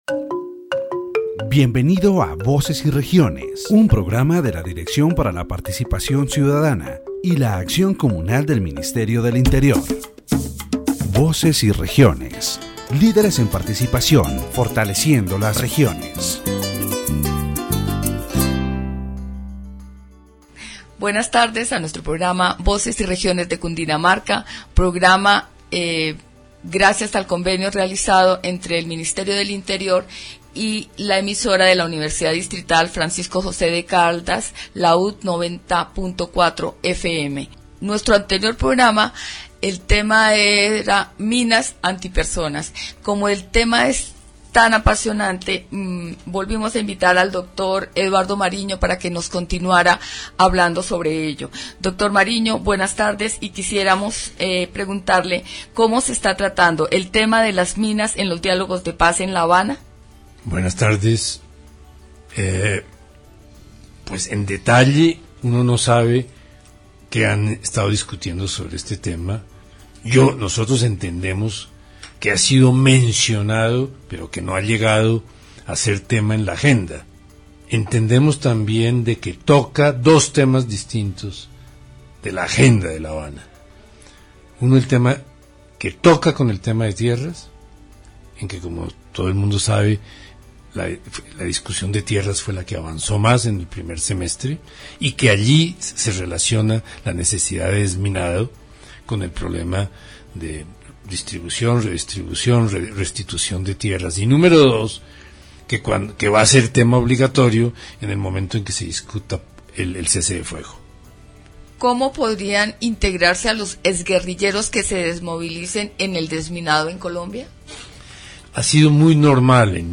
This section of the Voces y Regiones program addresses the issue of anti-personnel mines in Colombia, as a continuation of the previous episode (Programa Cundinamarca 23), but now within the context of the peace process taking place in Havana, Cuba. The interviewee shares their experience related to these devices within the framework of the Colombian conflict and human rights, detailing the severe consequences for those who are injured and mutilated, as well as the social and economic paralysis they cause in rural areas. Additionally, the discussion explains the manufacturing processes, the methods used to deploy these mines in different territories, and the high cost the State must bear for their removal throughout the country.